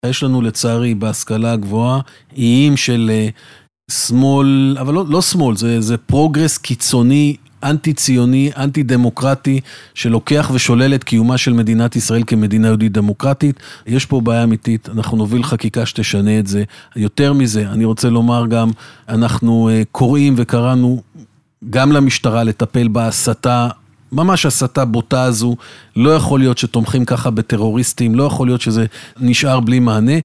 השר קיש בריאיון בתוכנית "הנבחרים" ברדיוס 100FM